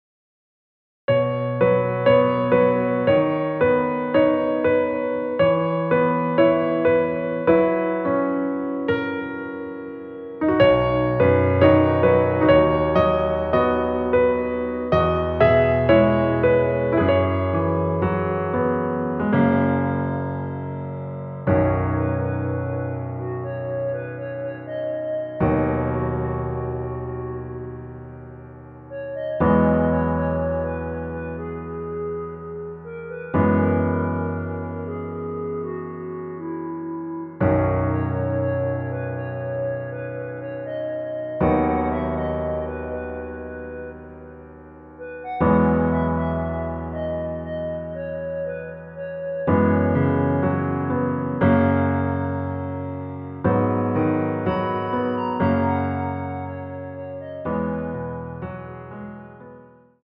원키에서(-2)내린 멜로디 포함된 MR입니다.
앞부분30초, 뒷부분30초씩 편집해서 올려 드리고 있습니다.
중간에 음이 끈어지고 다시 나오는 이유는